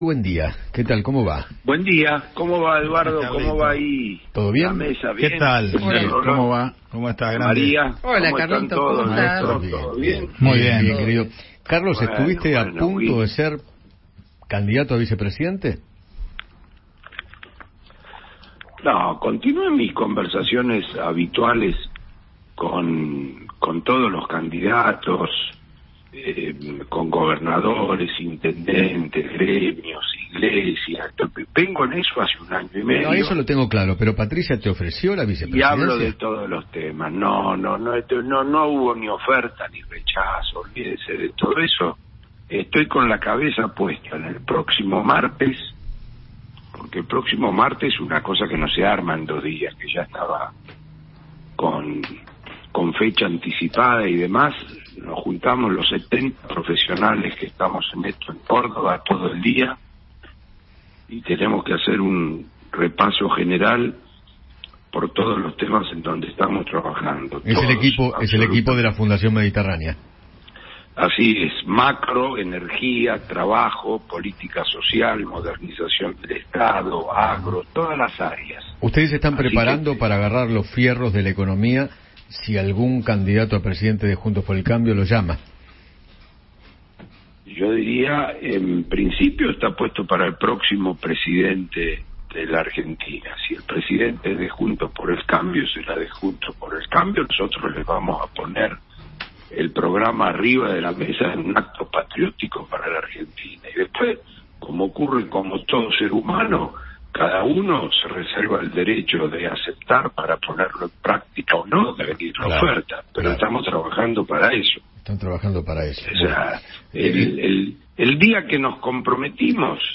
El economista Carlos Melconian habló con Eduardo Feinmann sobre la posibilidad de participar en el próximo Gobierno y manifestó qué medidas se deberían llevar a cabo para sanear la economía.